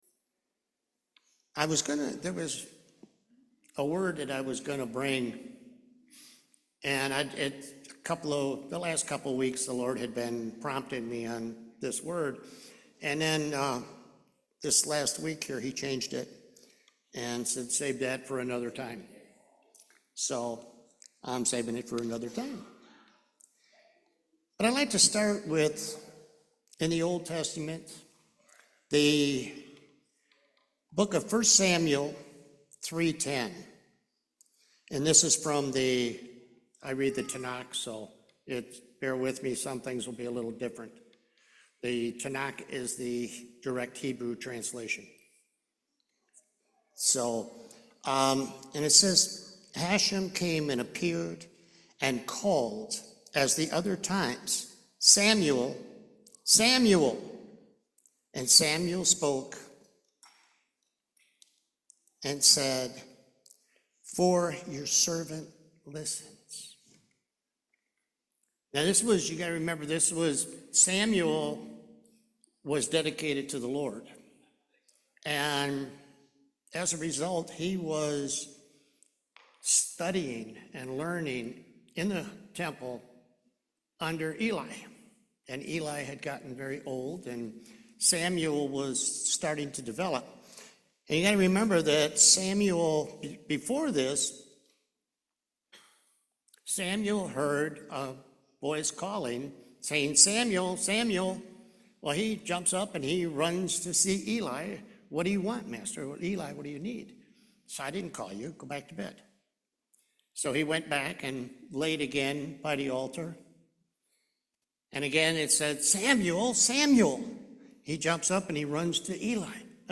Matthew 4:3-4 Service Type: Main Service Sheep know their shepherd’s voice.